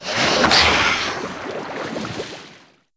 c_seasnake_atk3.wav